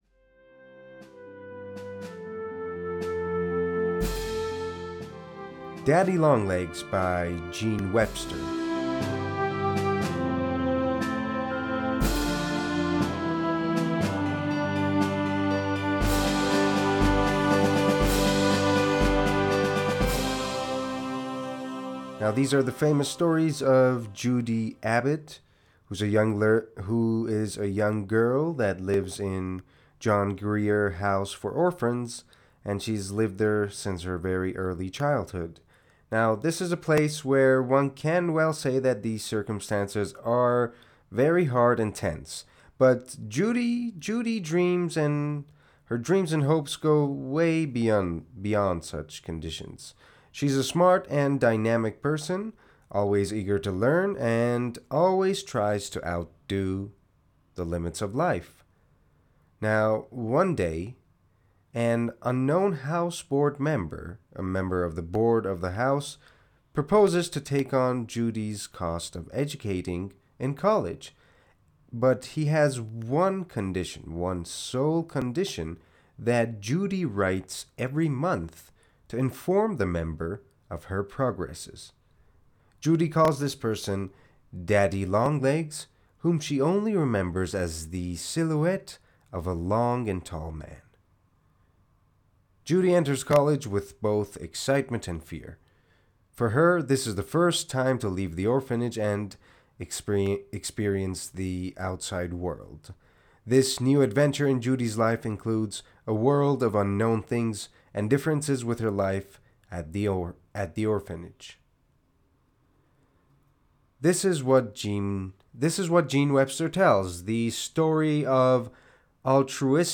معرفی صوتی کتاب Daddy Long Legs